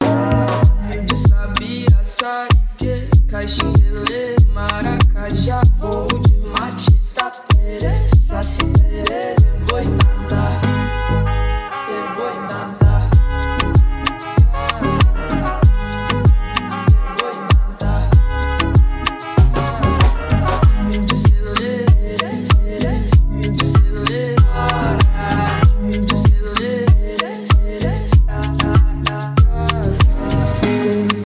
поп
латинские